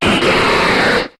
Cri de Sharpedo dans Pokémon HOME.